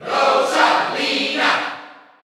Category: Crowd cheers (SSBU) You cannot overwrite this file.
Rosalina_&_Luma_Cheer_Dutch_SSBU.ogg